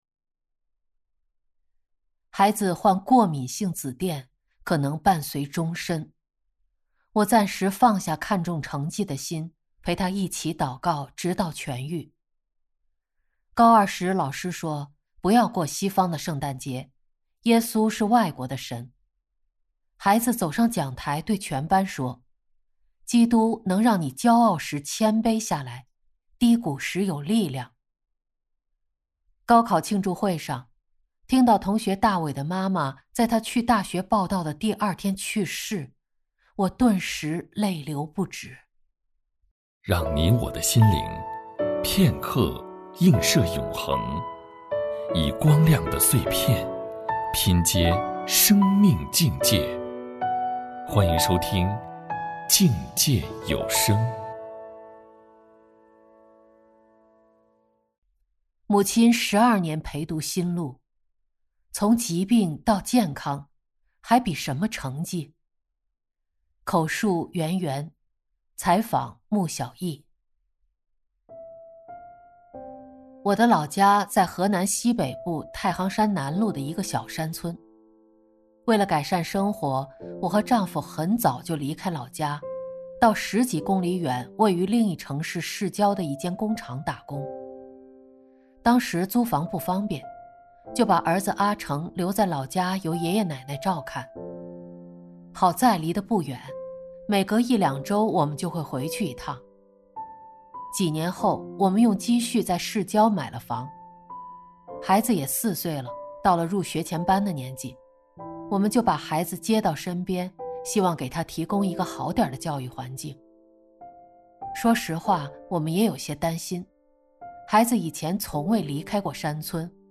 口述实录